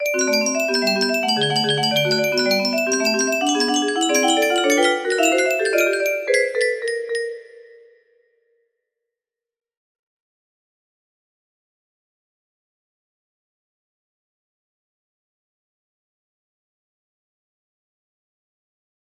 Unknown Artist - epic test music box melody